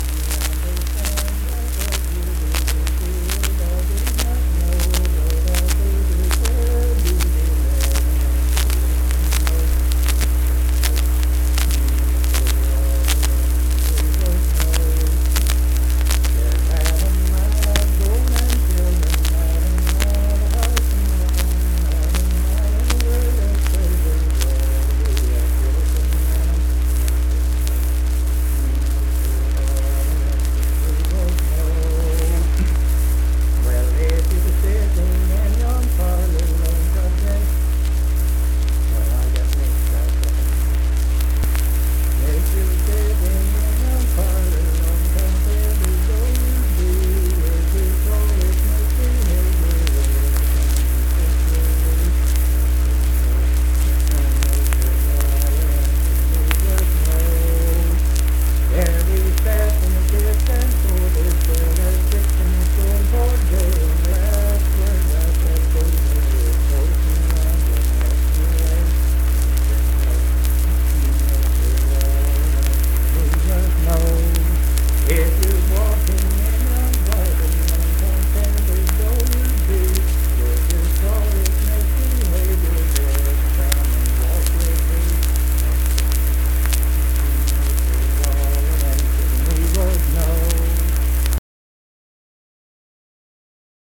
Unaccompanied vocal music
Verse-refrain 5(6w/R).
Performed in Kanawha Head, Upshur County, WV.
Dance, Game, and Party Songs
Voice (sung)